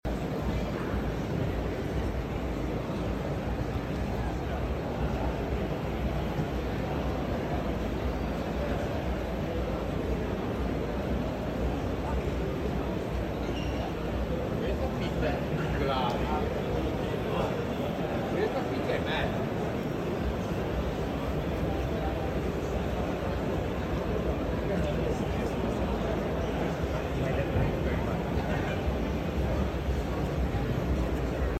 2024 KOVE MX250 motocross bike. sound effects free download